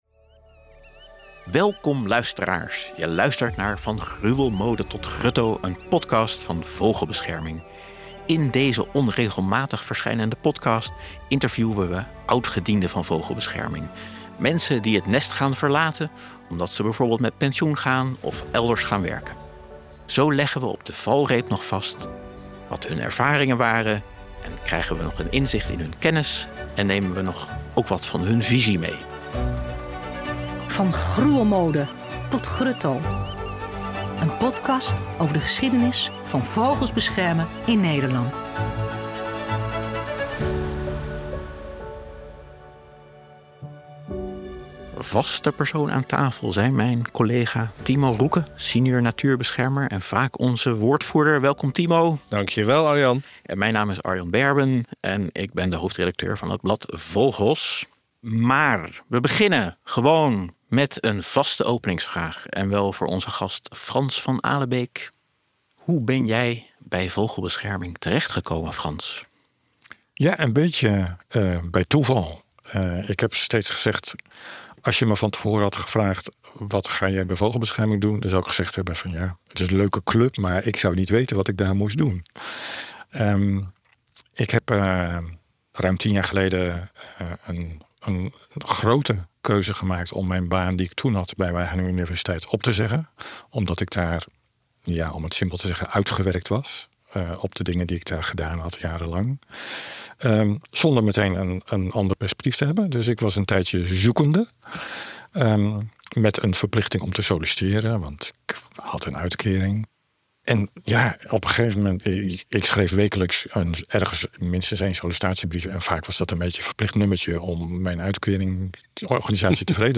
blikken terug met oudgedienden van Vogelbescherming Nederland.